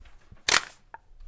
Gun picked up (Loaded)